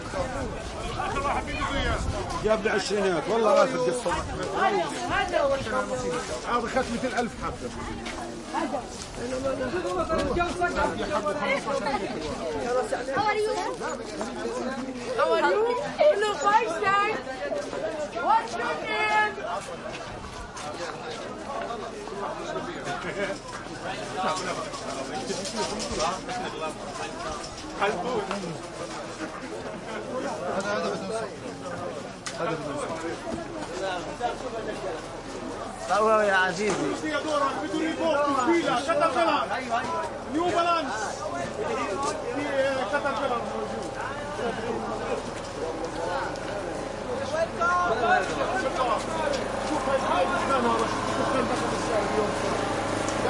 瓦拉画廊 1
描述：在蒙大拿州利文斯顿的一个小型画廊人群的录音。大量的再混响（自然）和回声的声音。用Zoom H4N以96K 24bit录制。
Tag: 人群 回声 画廊 声音 沃拉